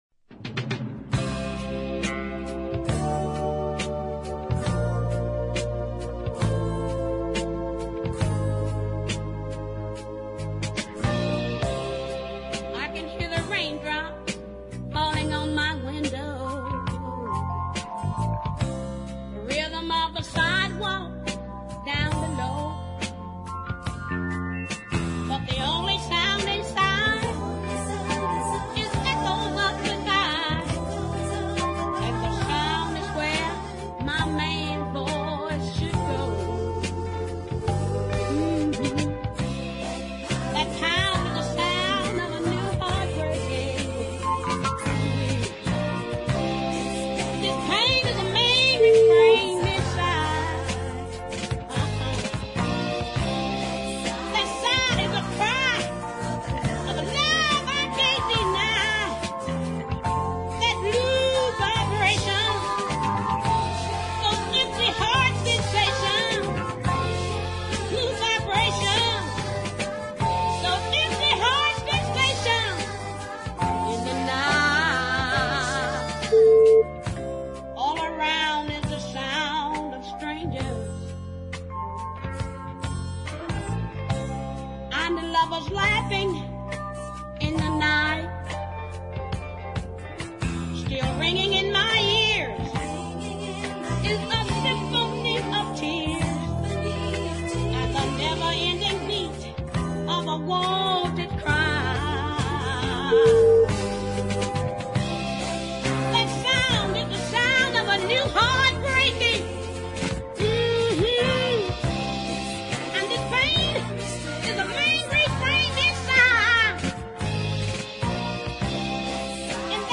delicate ballad